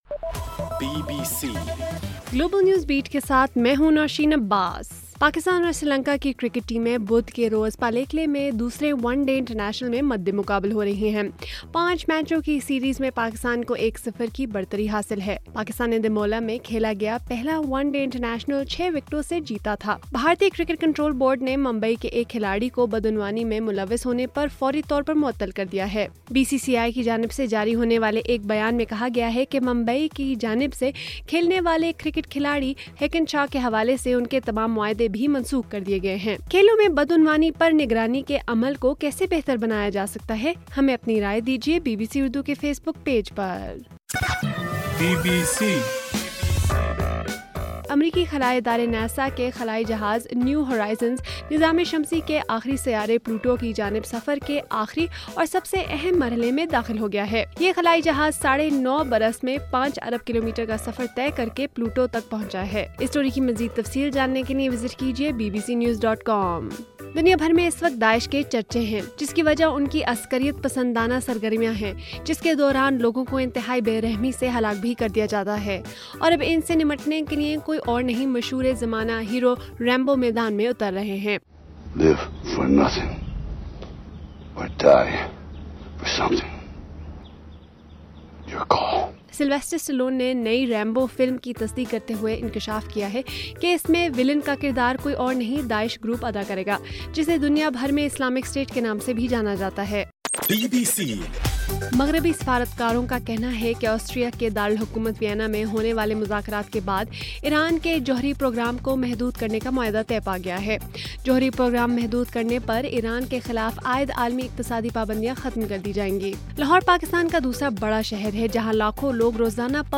جولائی 14: رات 10 بجے کا گلوبل نیوز بیٹ بُلیٹن